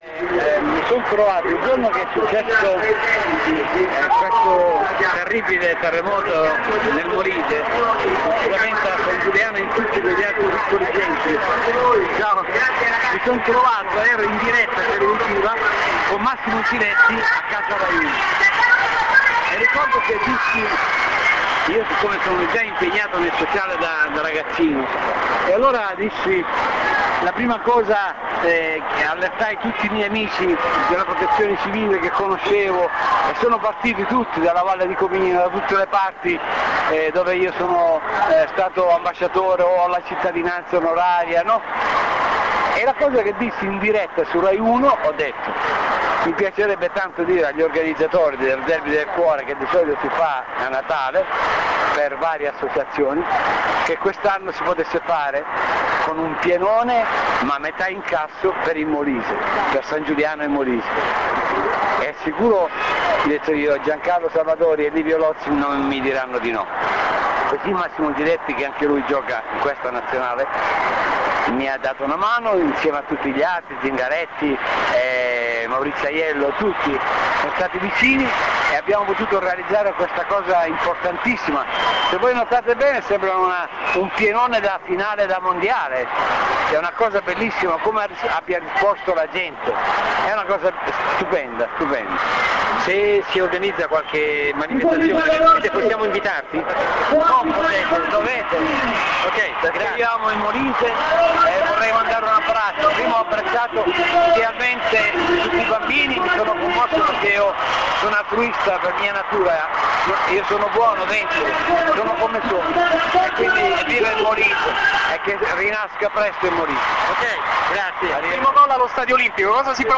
Speciale Derby del Cuore - INTERVISTE AI PERSONAGGI